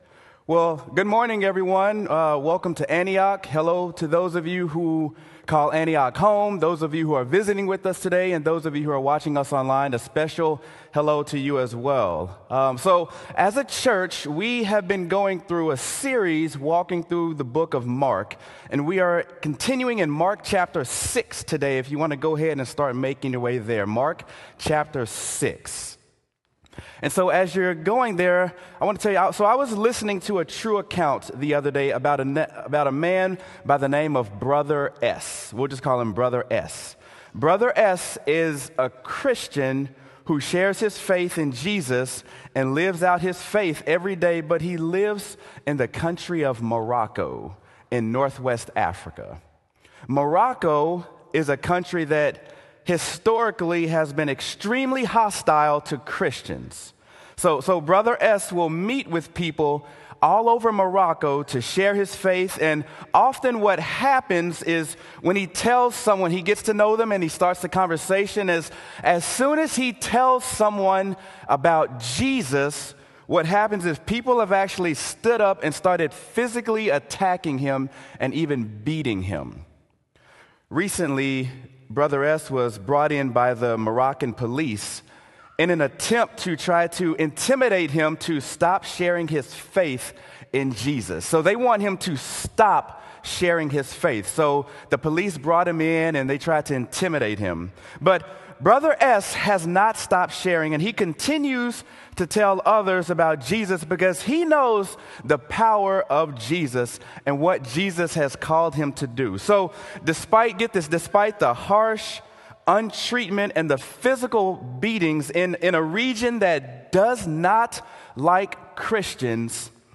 Sermon: Mark: Standing Strong